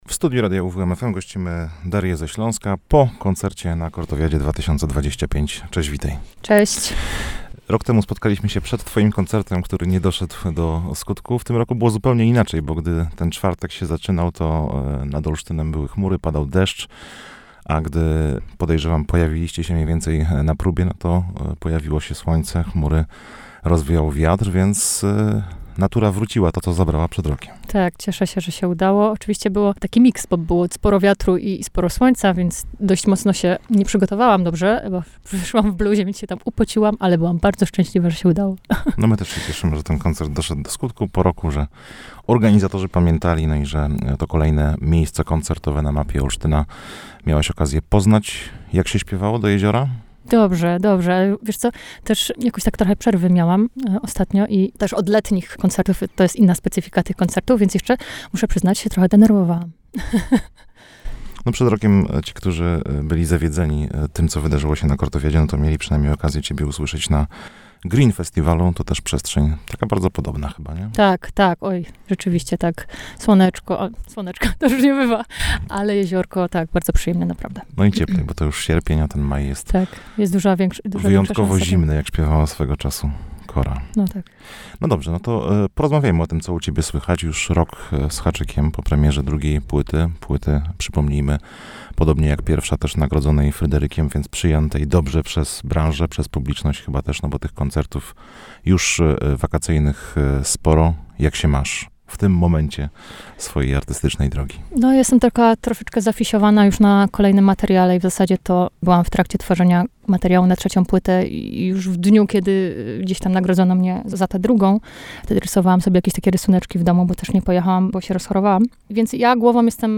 Odwiedziła też studio Radia UWM FM.